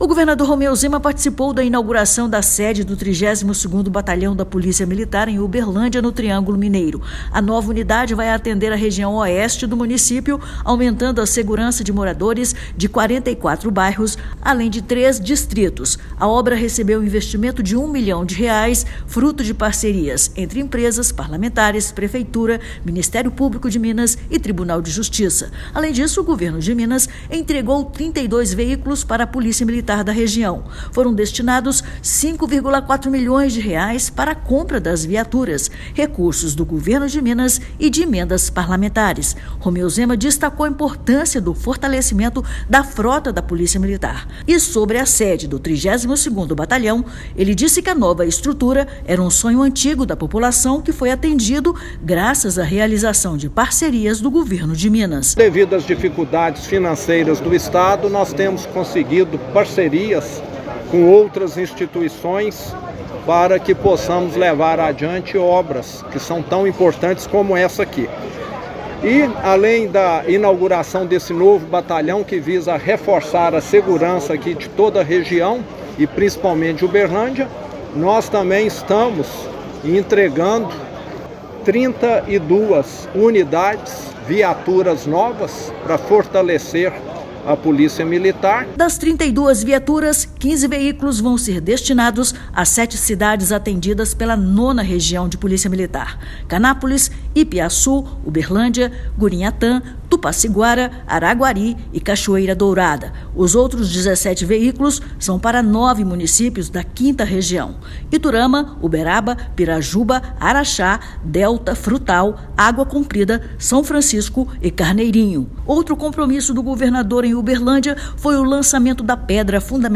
O governador Romeu Zema participou da inauguração da sede do 32º Batalhão da Polícia Militar, em Uberlândia, no Triângulo Mineiro. Além disso, o Governo de Minas entregou 32 veículos para a PM da região. Ouça matéria de rádio.